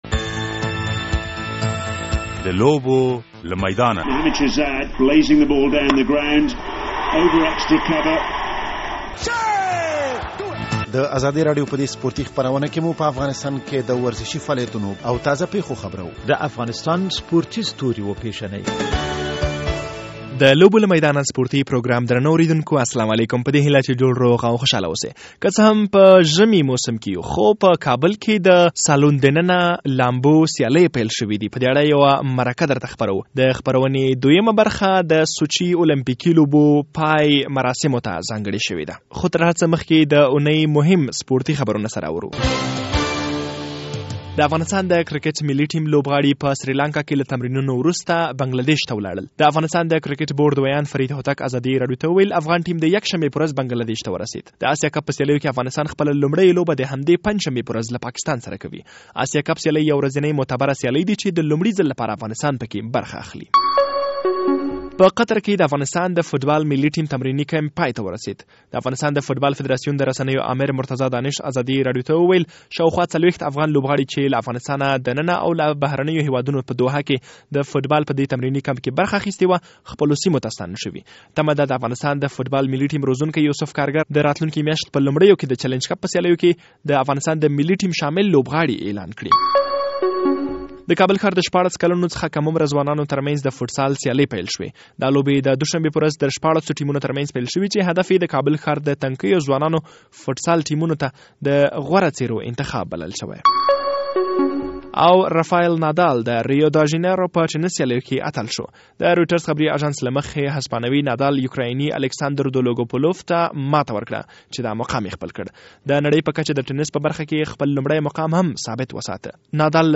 په ژمي کې د صالون دننې لامبو لوبې او د سوچي ژمني المپيک پای مراسم هغه څه دي چې په روان پروګرام کې یې په اړه رپوټ او مرکه اوریدلئ شئ.